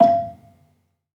Gambang-E4-f.wav